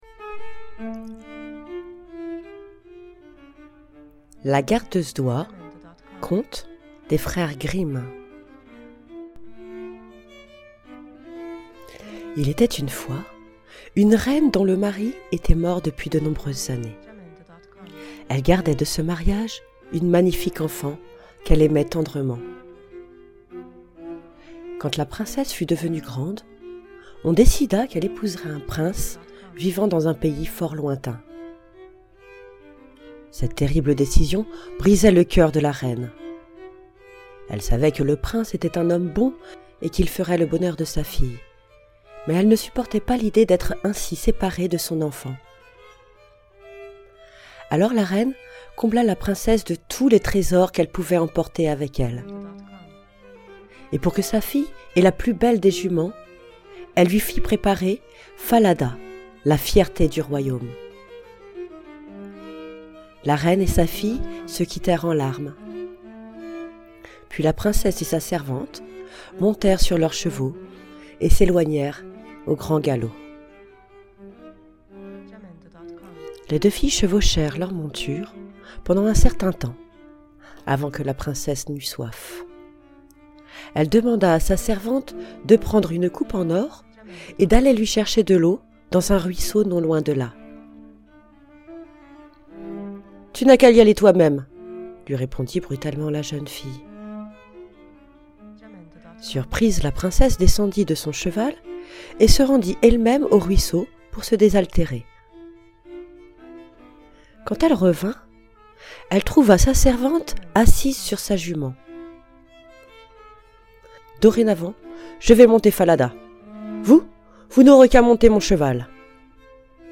La gardeuse d'oies, conte des frères Grimm Jacob et Wilhelm. Histoire lue dans sa version Les contes de Grimm aux éditions Piccolia 2015